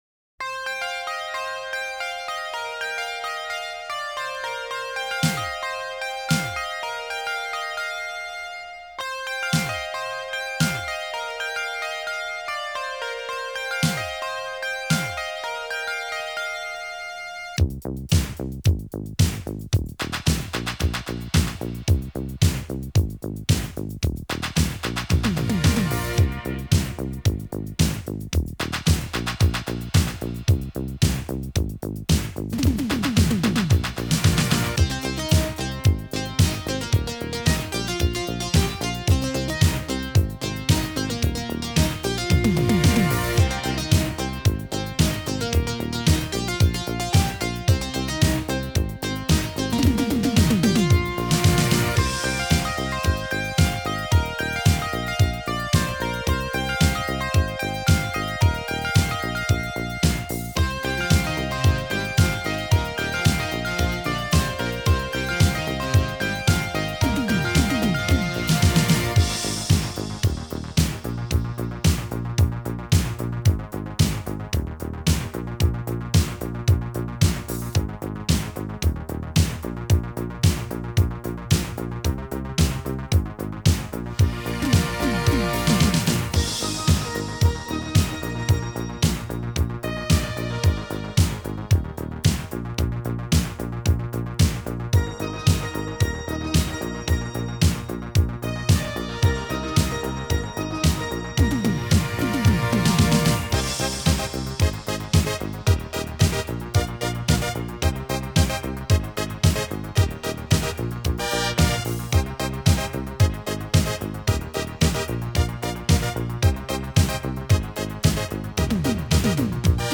Start Your ’80s Italo Collection Today